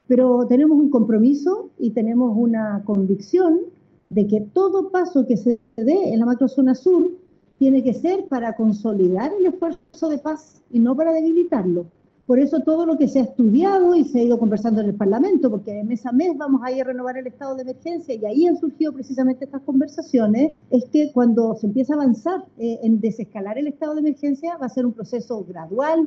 En este lugar, la ministra del Interior ofreció un punto de prensa, dónde dijo que si en algún momento se toma la decisión de levantar el estado de excepción, será un proceso gradual porque el Gobierno no va a retroceder en los avances que ha tenido en materia de seguridad.